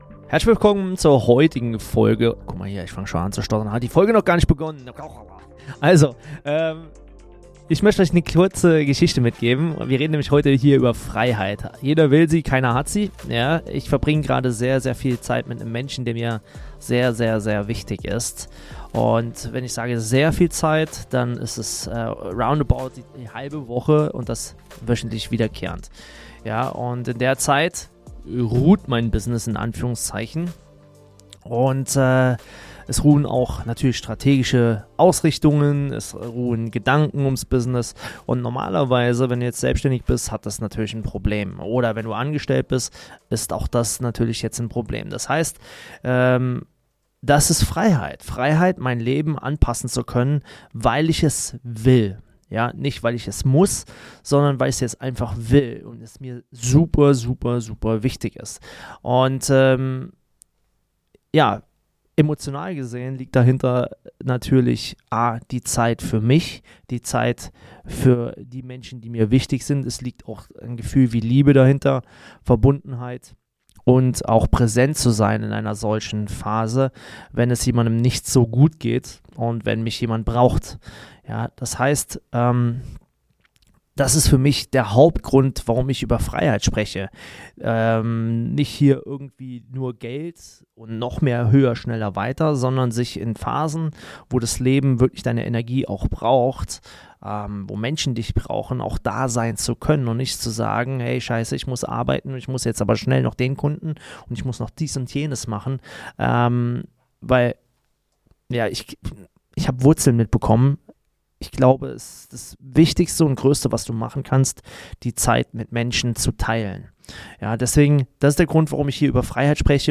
Persönlich, klar, ohne Show.